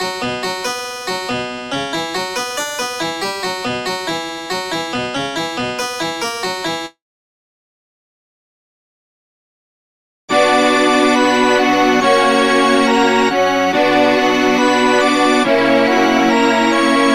harpsichord
organ and strings